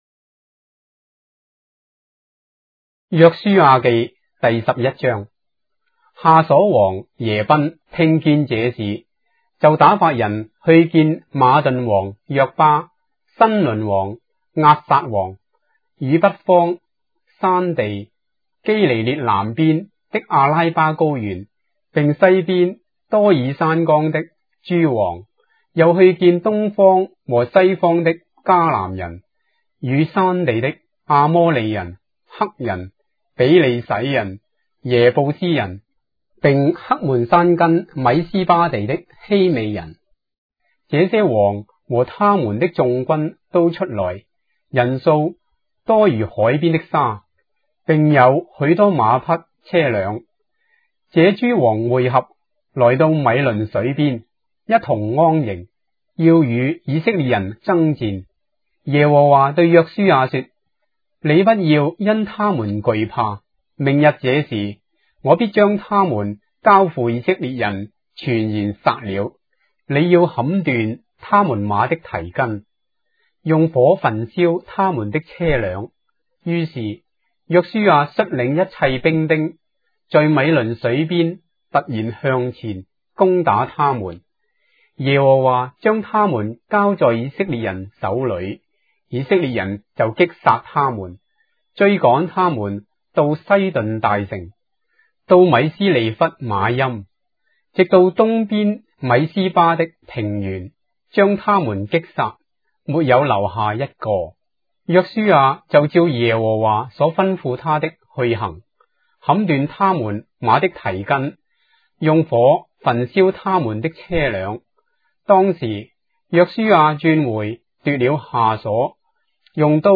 章的聖經在中國的語言，音頻旁白- Joshua, chapter 11 of the Holy Bible in Traditional Chinese